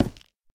Minecraft Version Minecraft Version 1.21.5 Latest Release | Latest Snapshot 1.21.5 / assets / minecraft / sounds / block / nether_wood / break3.ogg Compare With Compare With Latest Release | Latest Snapshot
break3.ogg